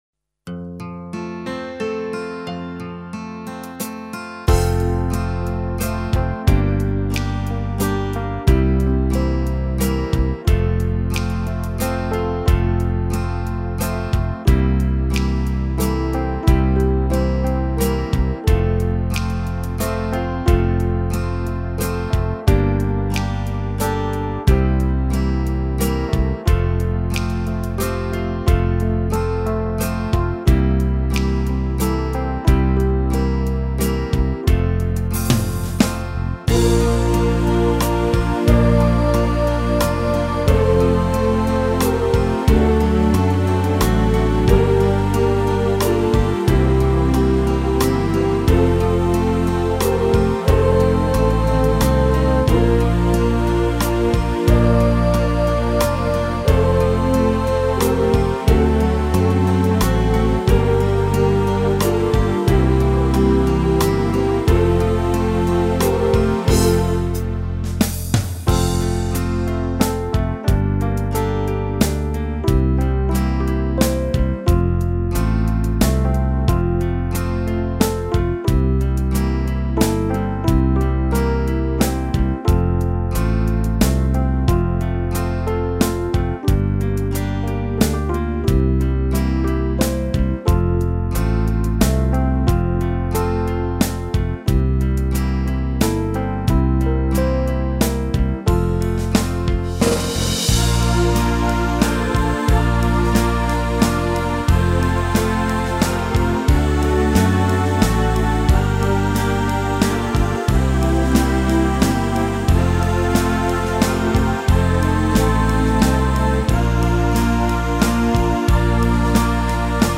Boston